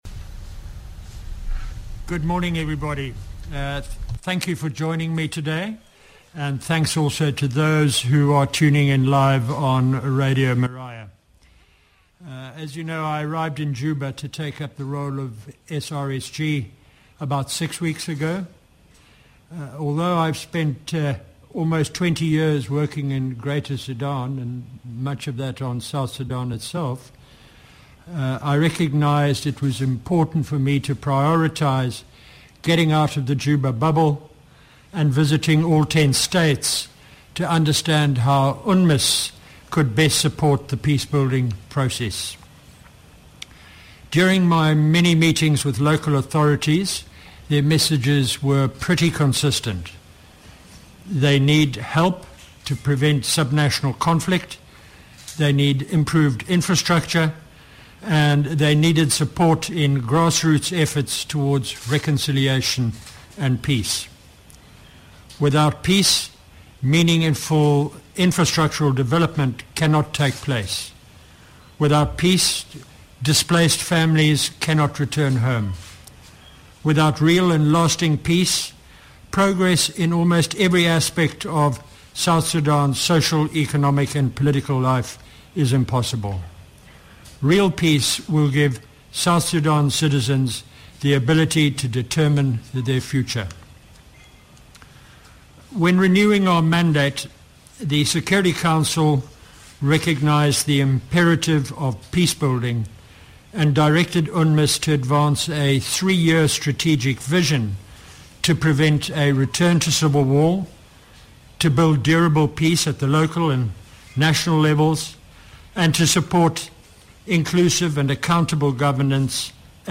SRSG Nicholas Haysom addresses press conference in Juba